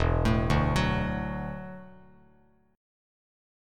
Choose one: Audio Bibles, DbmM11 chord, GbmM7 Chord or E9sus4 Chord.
E9sus4 Chord